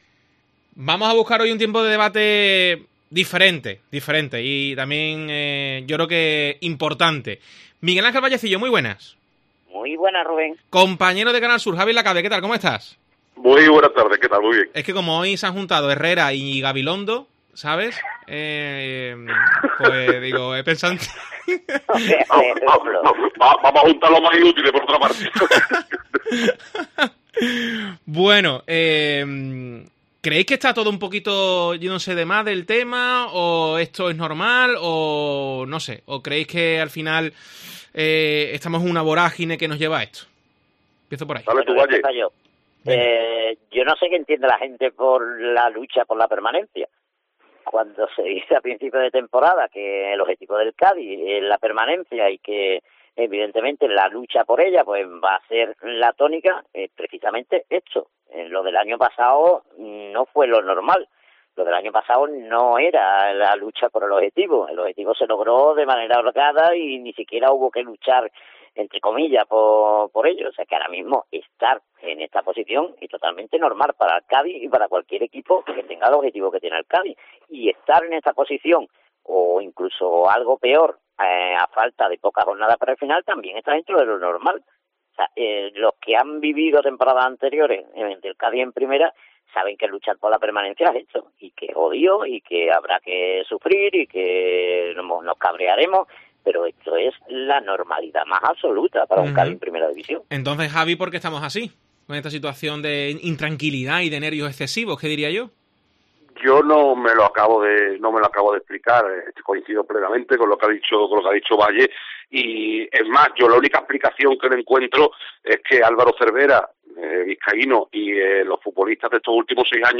Escucha el debate en Deportes COPE Cádiz